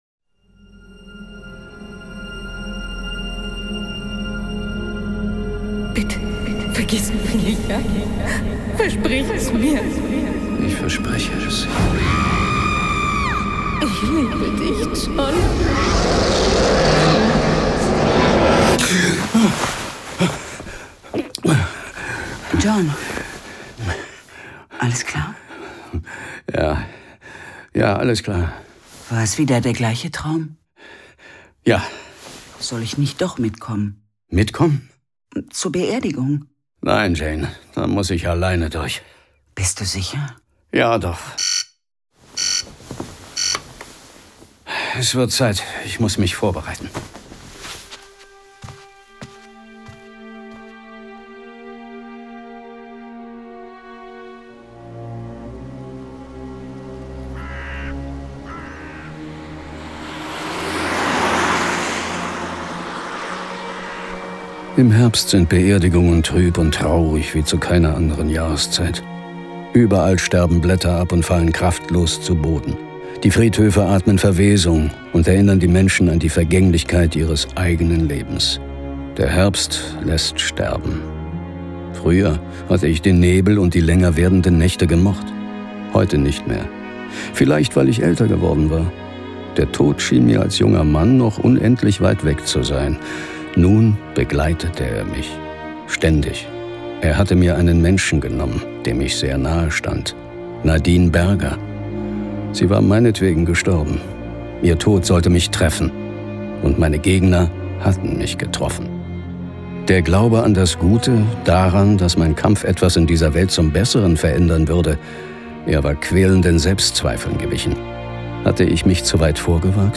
John Sinclair - Folge 55 Fenris, Der Götterwolf. Hörspiel.